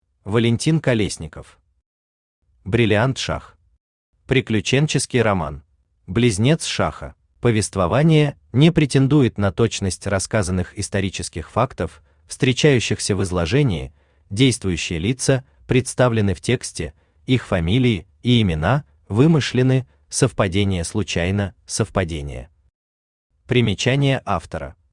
Аудиокнига Бриллиант Шах. Приключенческий роман | Библиотека аудиокниг
Приключенческий роман Автор Валентин Колесников Читает аудиокнигу Авточтец ЛитРес.